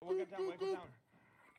goop goop goop Meme Sound Effect
goop goop goop.mp3